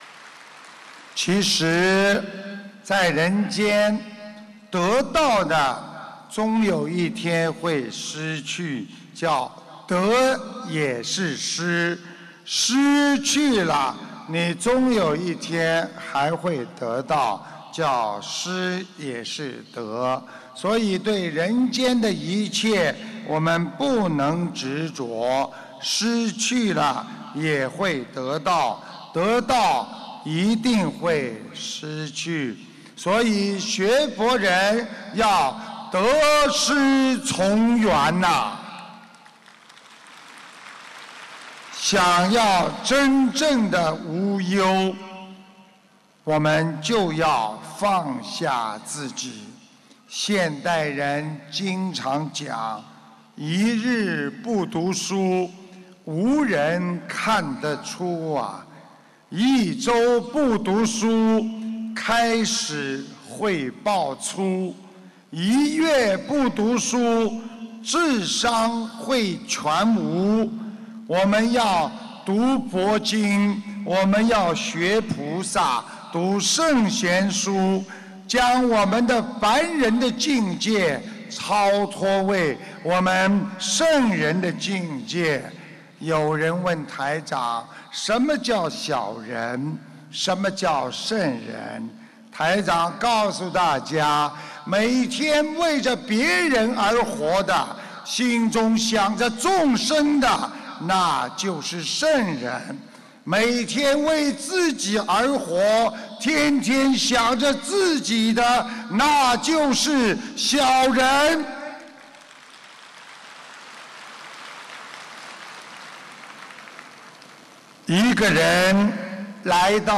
马来西亚槟城